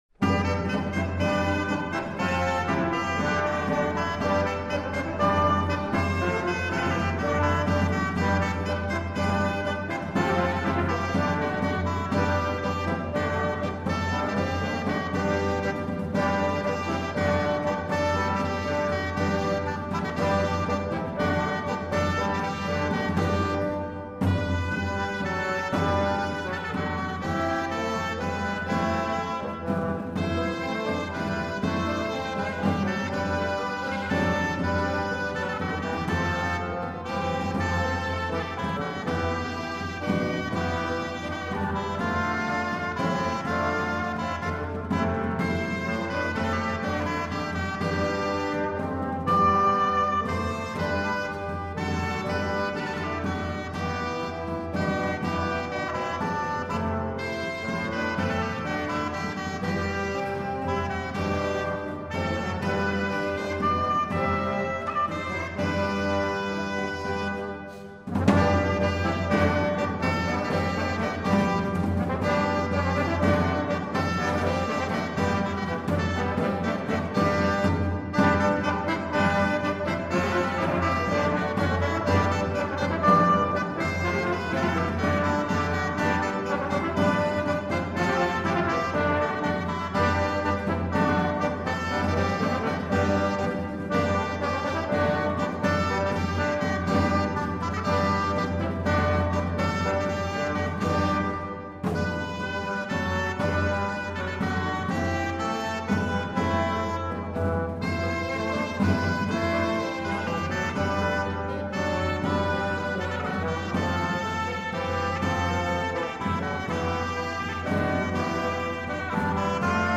La puntata di Quilisma prende spunto da un concerto dell’ensemble Anonima Frottolisti nell’ambito della rassegna Cantar di Pietre . Un concerto che ha raccontato lo spaccato “popolareggiante”, per quanto estremamente colto, del gusto musicale cortese e profano del XV e XVI secolo.